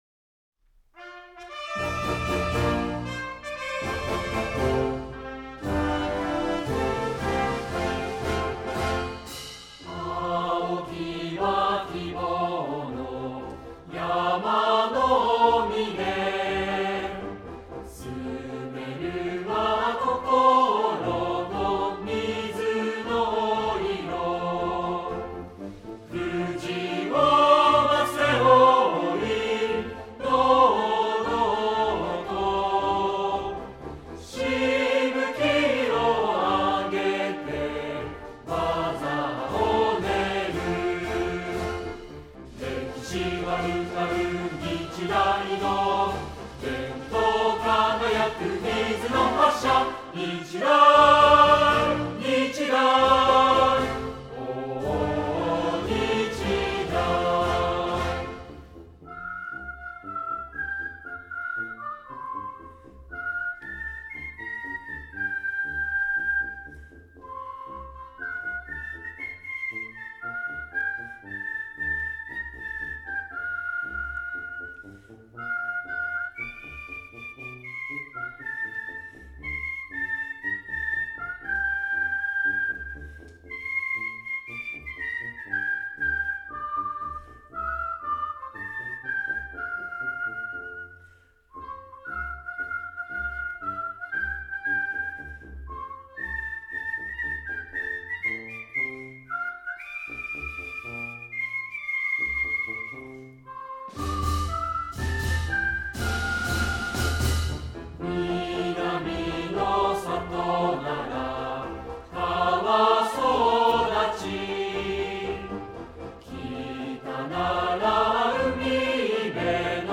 ※２番口笛